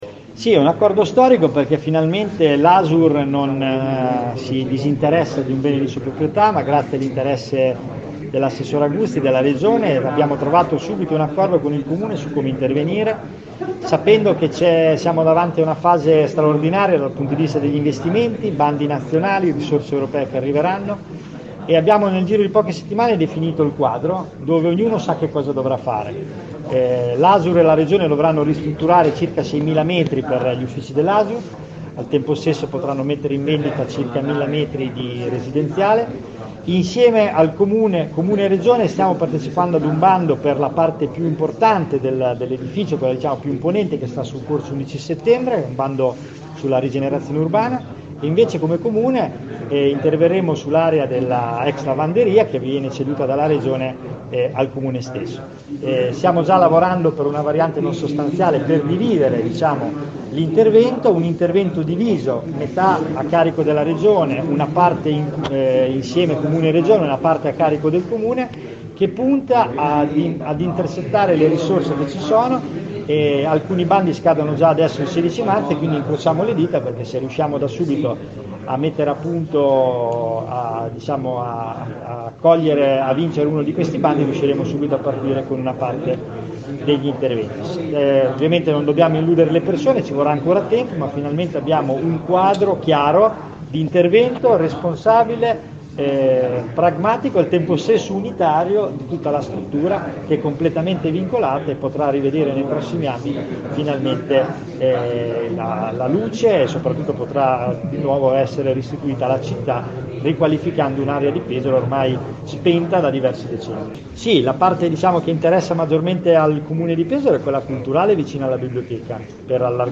Definito dal Sindaco di Pesaro Matteo Ricci, “un accordo storico” sono state illustrate le intese tra Regione Marche e Comune di Pesaro, riguardanti la riqualificazione dell’ex ospedale psichiatrico San Benedetto di Pesaro. Ai nostri microfoni: Matteo Ricci, Sindaco di Pesaro e Stefano Aguzzi, Assessore della Regione Marche.